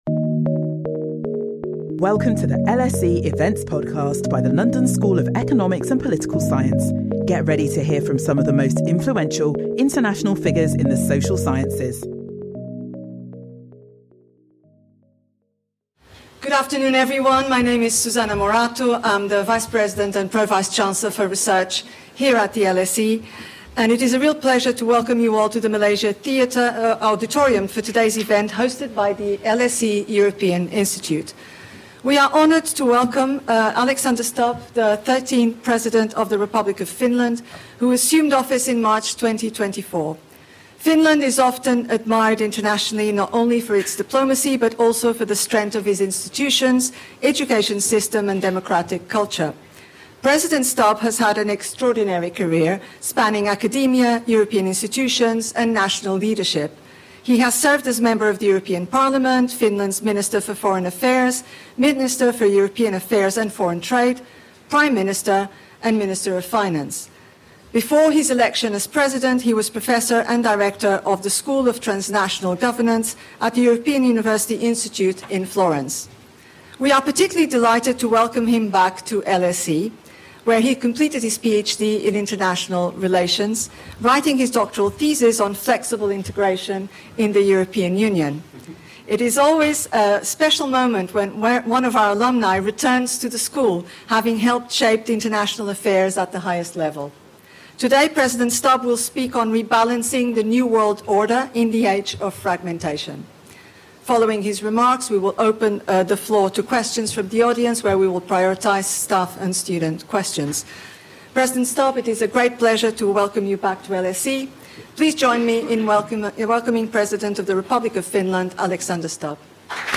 Join us for this special event with LSE alumnus and President of Finland Alexander Stubb.
Speaker Dr Alexander Stubb